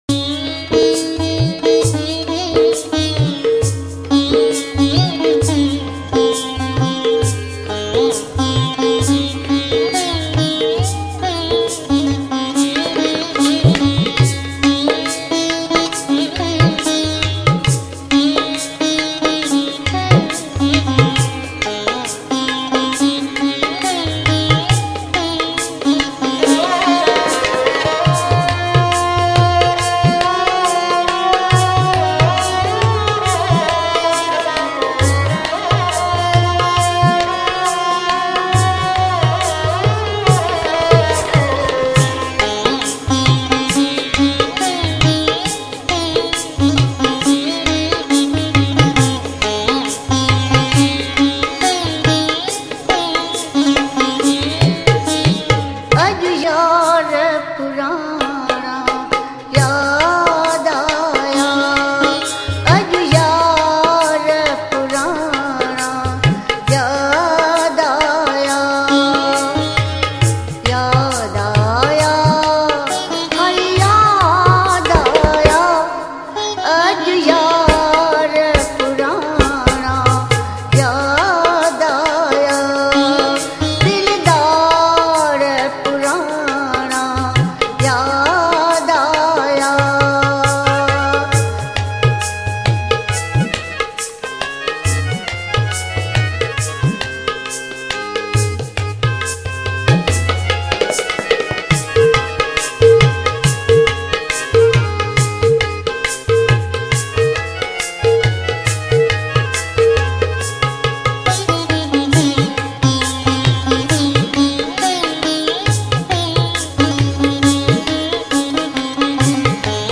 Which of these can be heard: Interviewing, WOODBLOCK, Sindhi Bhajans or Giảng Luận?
Sindhi Bhajans